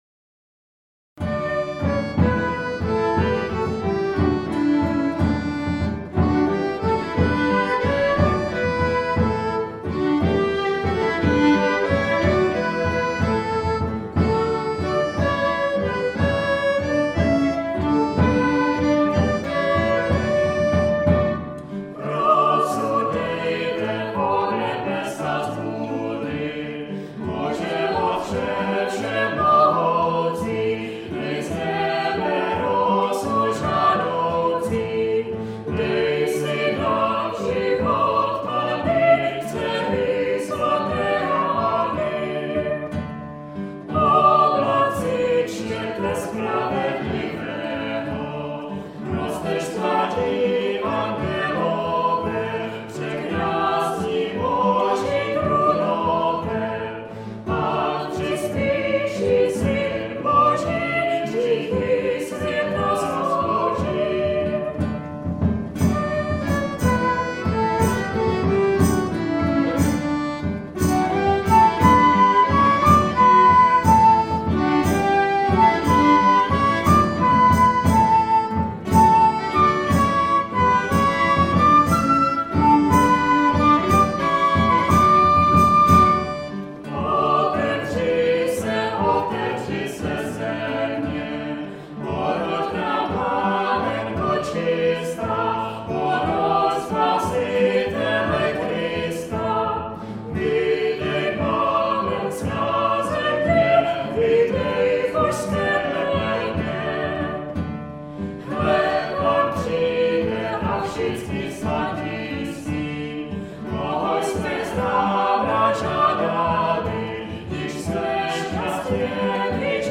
nahrávka z koncertu